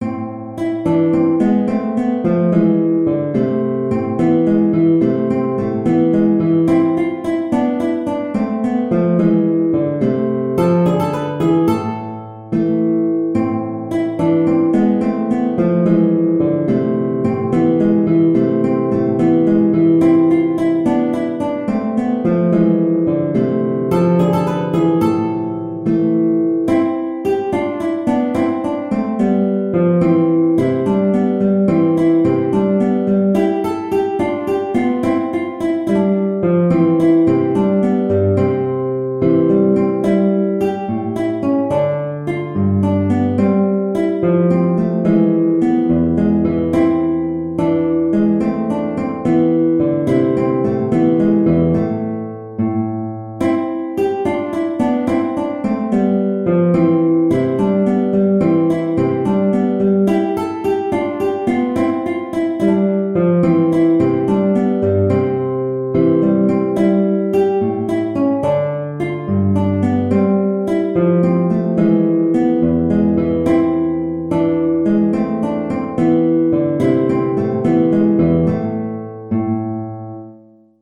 A minor (Sounding Pitch) (View more A minor Music for Guitar )
6/8 (View more 6/8 Music)
E3-B5
Guitar  (View more Intermediate Guitar Music)
Traditional (View more Traditional Guitar Music)